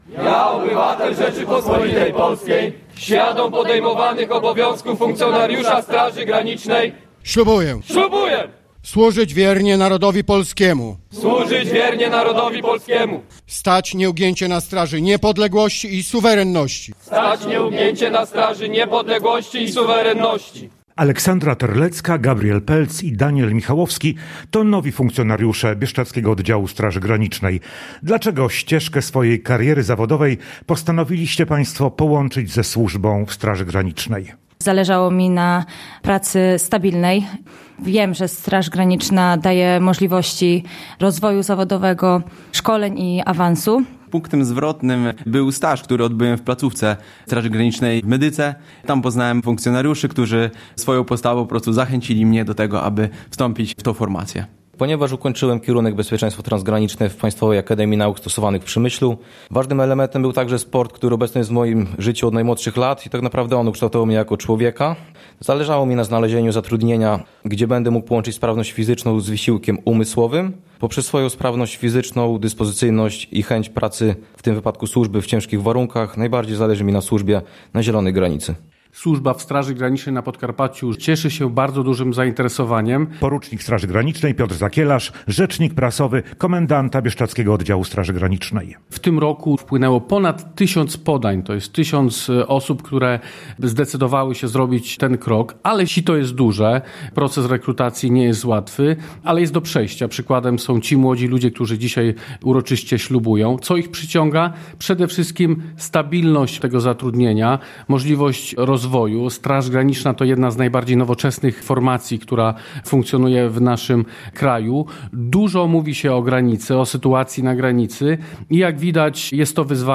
Relacje reporterskie • W Przemyślu odbyła się uroczystość ślubowania nowo przyjętych funkcjonariuszy Bieszczadzkiego Oddziału Straży Granicznej.
Ślubowanie nowych funkcjonariuszy Straży Granicznej w Przemyślu